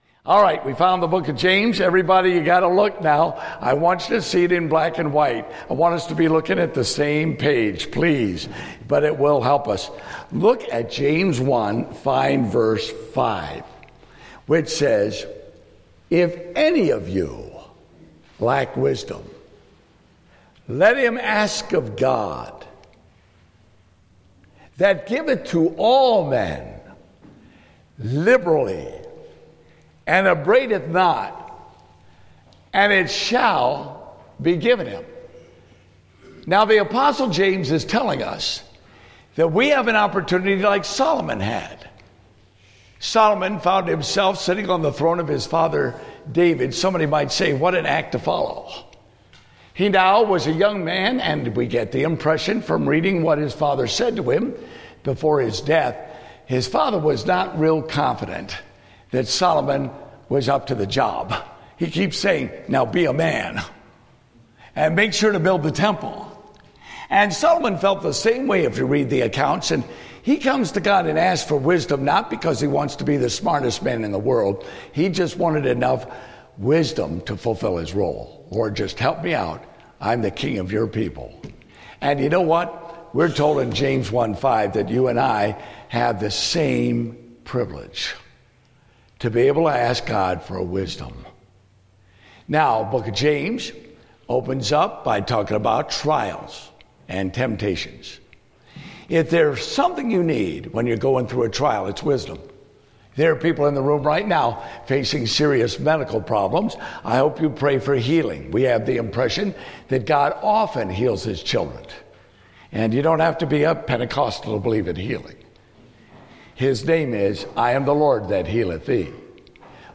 Date: August 21, 2015 (Family Camp)